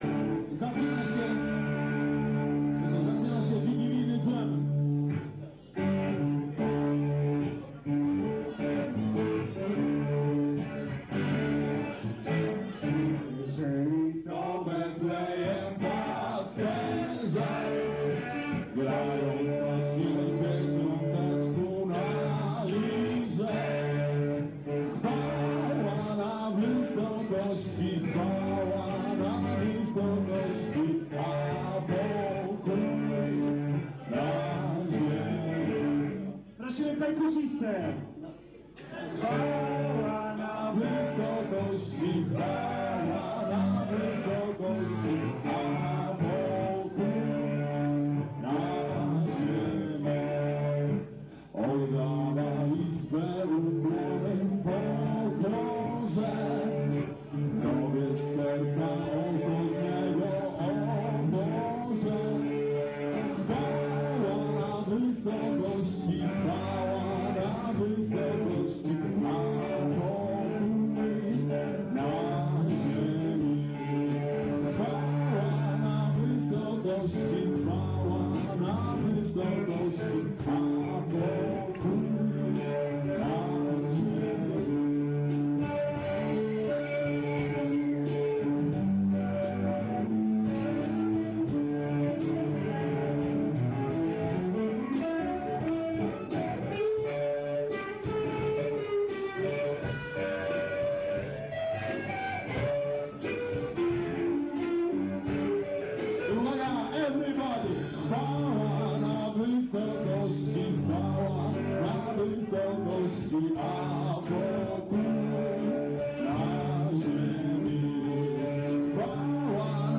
kONCERT SWIATECZNY
Klub IKS (dawniej Wysepka), 22/12/1999
Jakosc nagran bootlegowa!